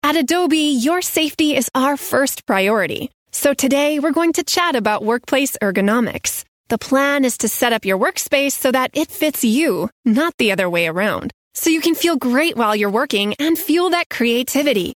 Corporate Narration
• Upbeat, Bright, Friendly - Adobe Health & Safety Onboarding